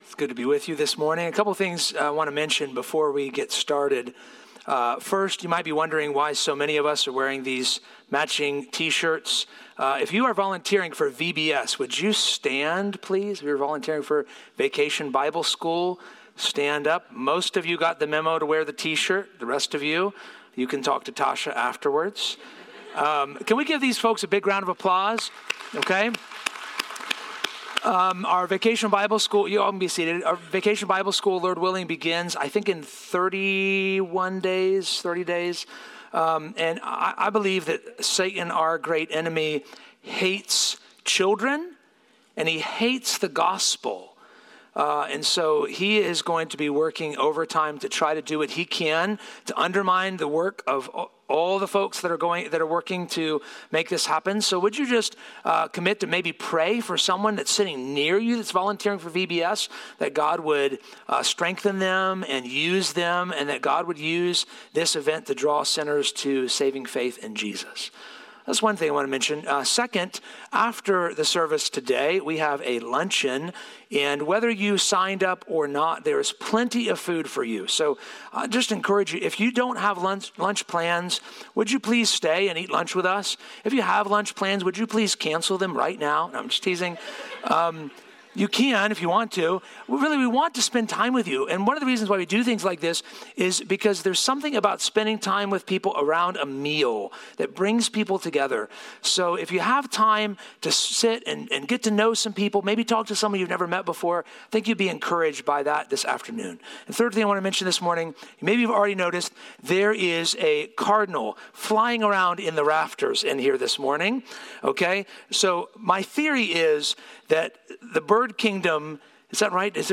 Sermons | Poquoson Baptist Church